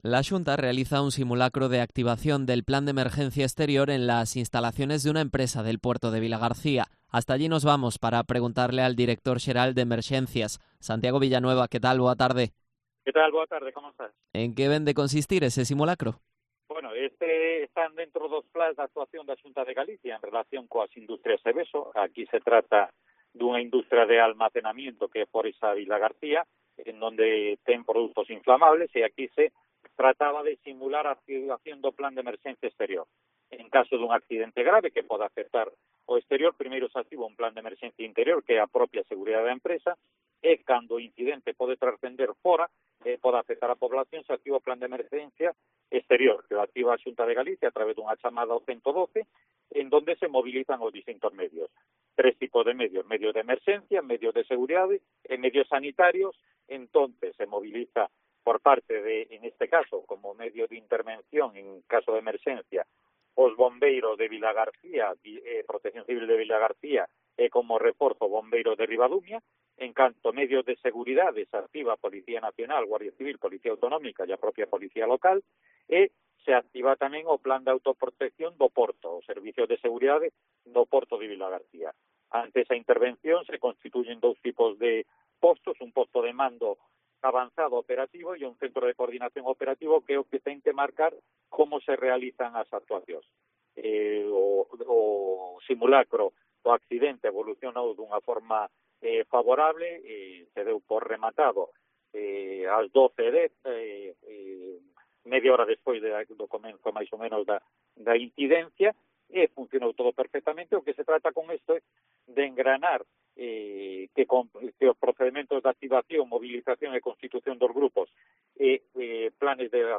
Entrevista a Santiago Villanueva, director xeral de Emerxencias